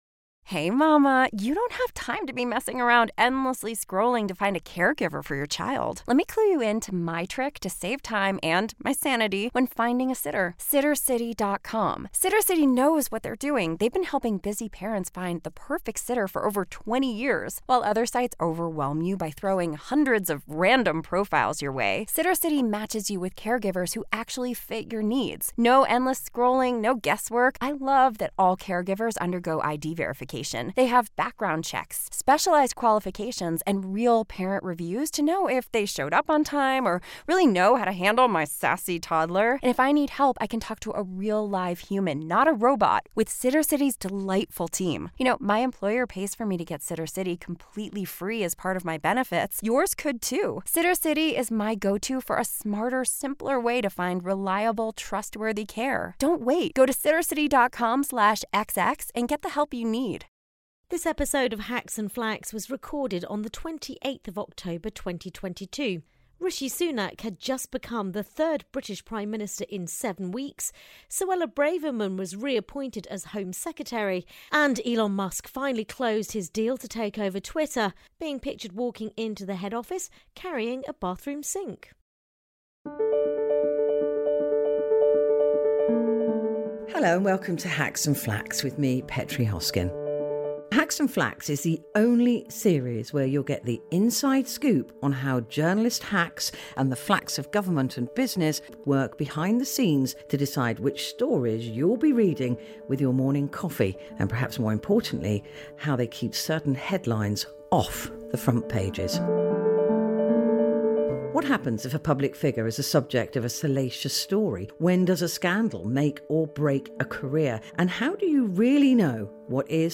Season 1, Episode 5, Jan 05, 2023, 01:00 AM Facebook Twitter Headliner Embed Embed Code See more options Recorded 28th October 2022 As Elon Musk became the owner of Twitter at the time of recording, this episode of Hacks and Flaks seemed like the perfect opportunity for the panel to discuss the phenomenon of social media journalism and its impact on traditional news gathering.